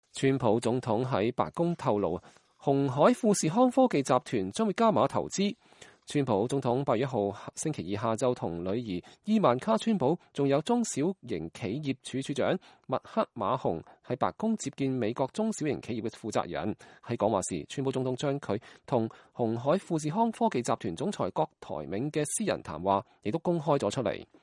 川普總統8月1號星期二下午，與女兒伊萬卡川普(Ivanka Trump)﹐還有中小企業署署長麥克馬洪(Linda McMahon)在白宮接見美國中小企業的負責人。在講話時，川普總統將他與鴻海富士康科技集團總裁郭台銘的私人談話公開出來。